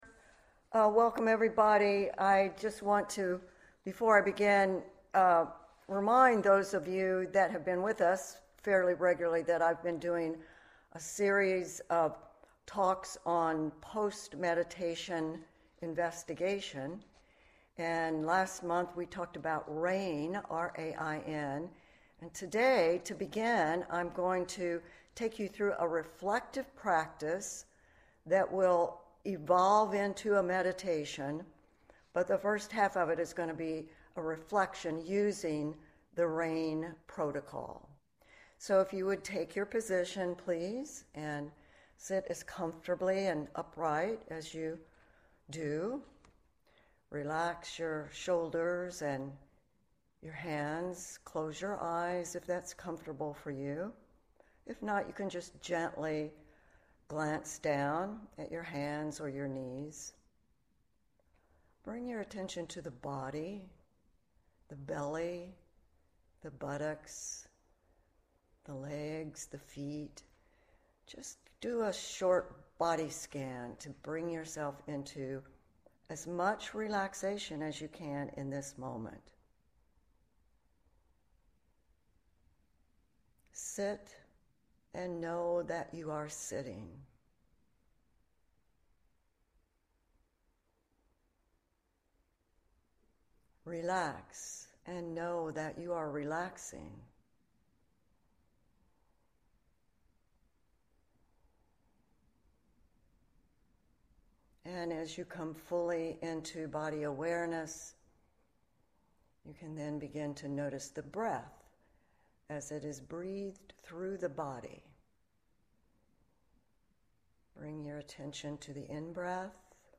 Guided Rain Contemplation